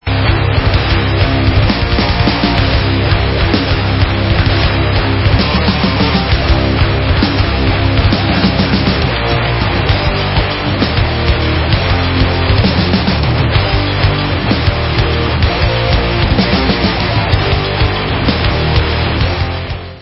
MONSTER TRUCK RIFFS
sledovat novinky v kategorii Rock